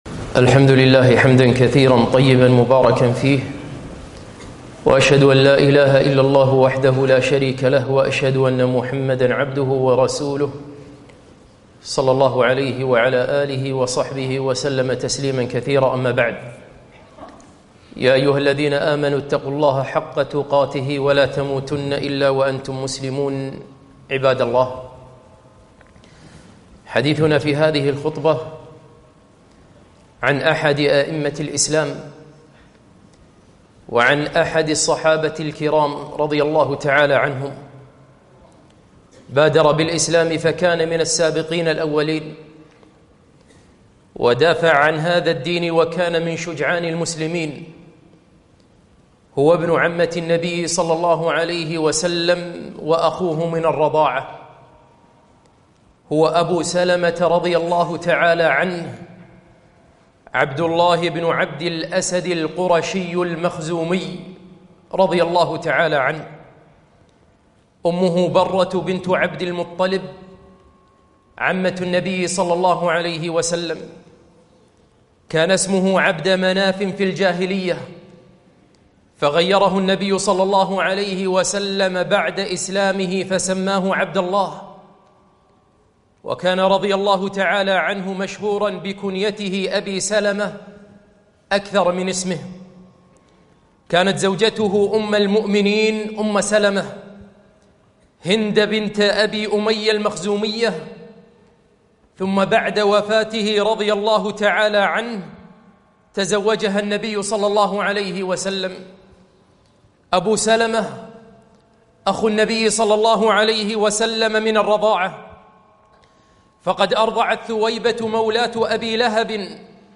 خطبة - أبو سلمة عبدالله بن عبدالأسد المخزومي رضي الله عنه